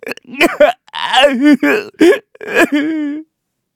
Kibera-Vox_Sad_kr.wav